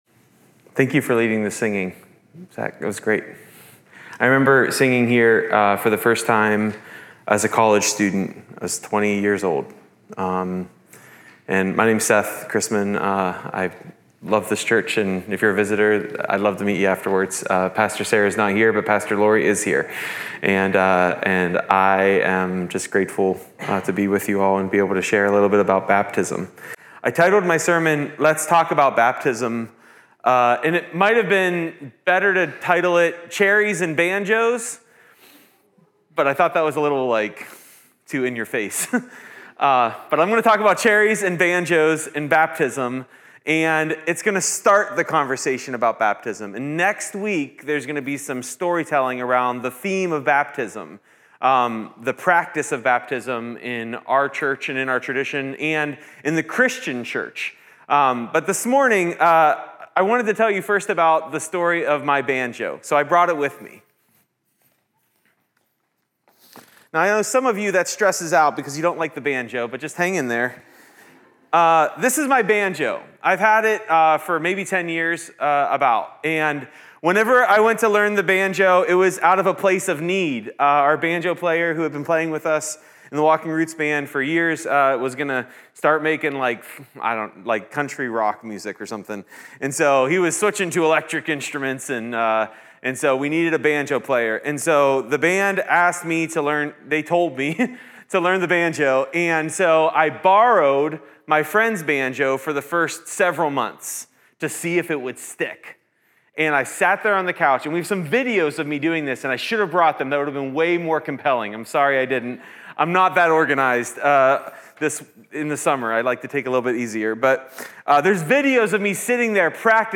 Revelation 1:4-9 Order of worship/bulletin Youtube video recording Sermon audio recording.